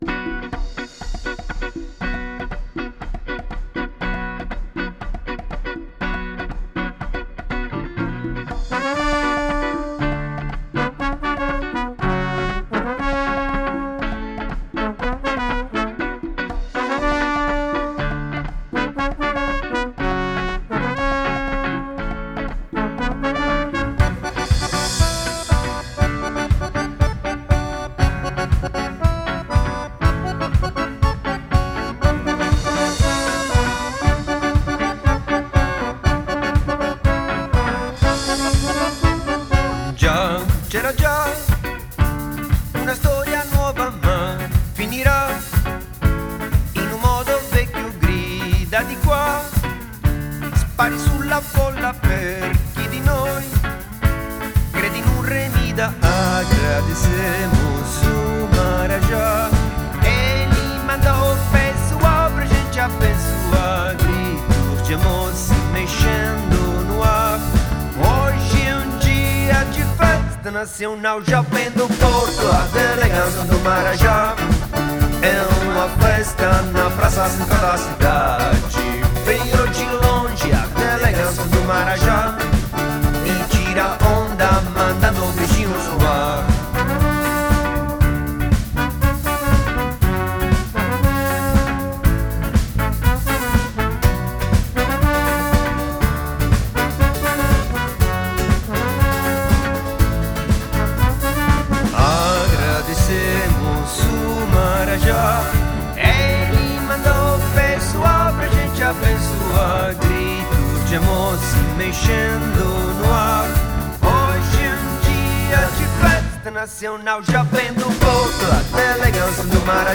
Il ritmo unico dello spaghetti samba da Bologna!